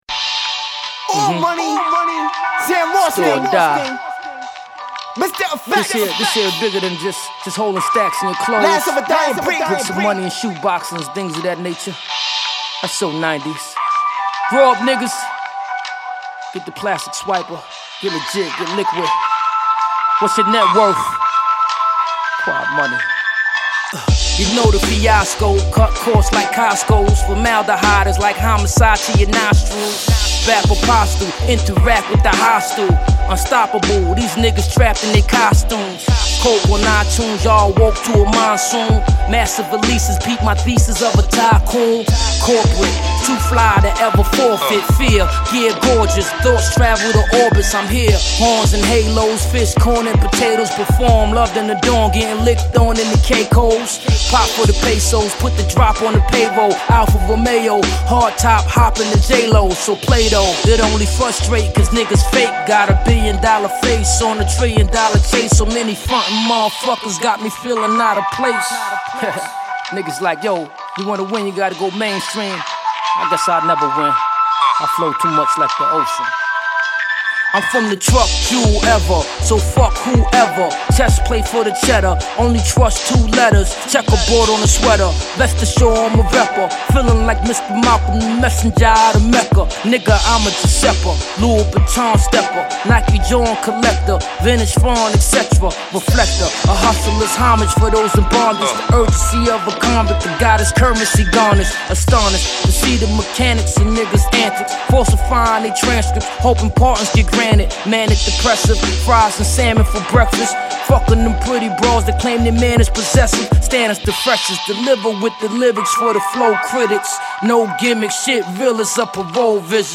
It’s a very smooth listen.